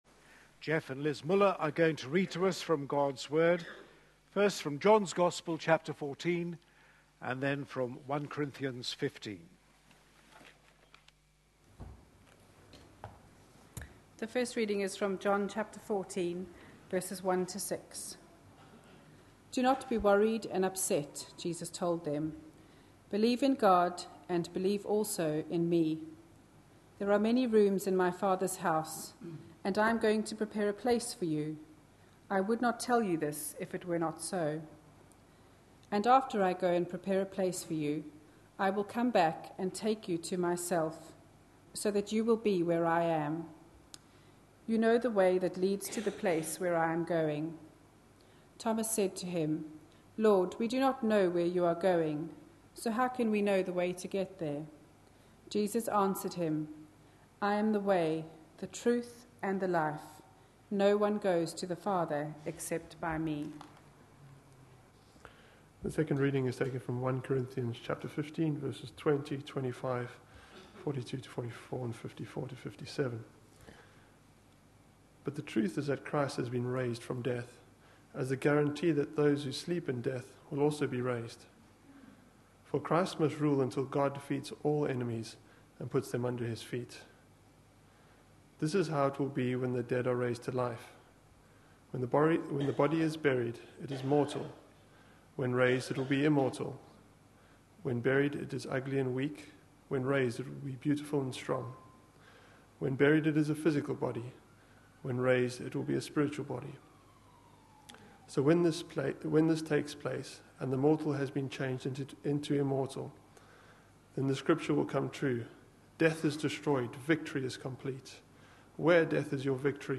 A sermon preached on 7th July, 2013, as part of our Four `Words' from 1 Corinthians. series.